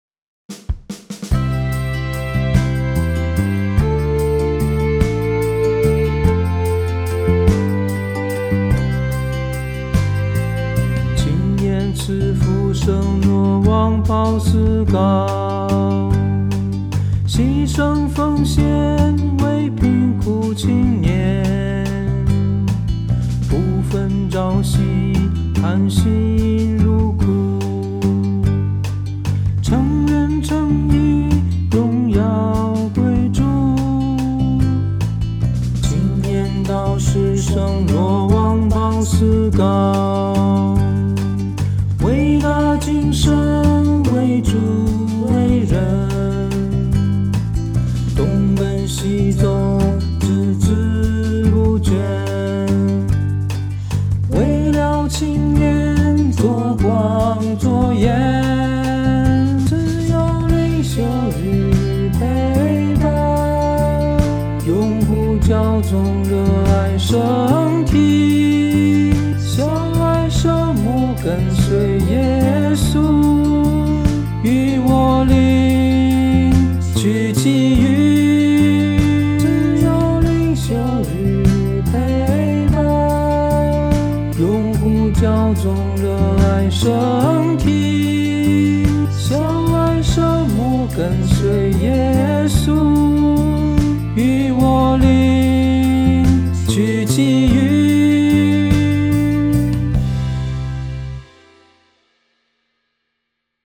【原创圣歌】|《予我灵，取其余》香港慈幼会修院五指连心参赛作品